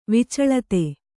♪ vicaḷate